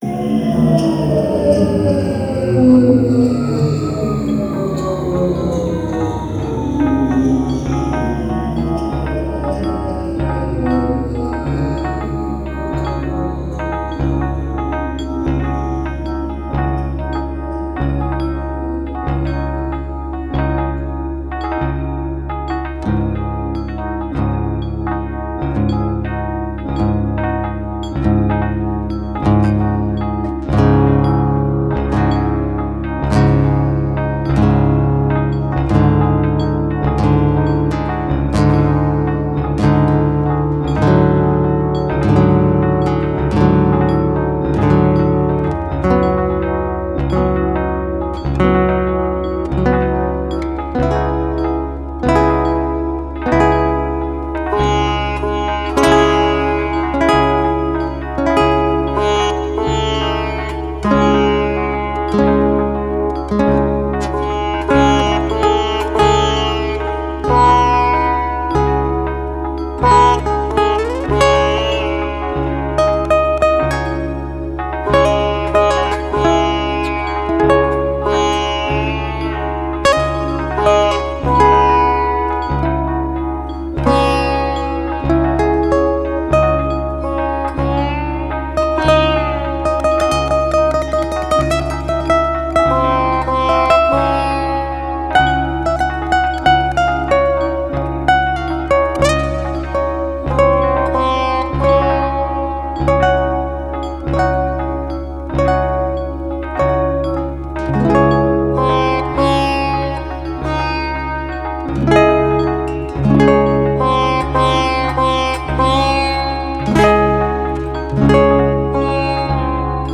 Alle Stücke auf dieser Seite liegen in CD-Qualität vor.
H2-Spektral-Glocken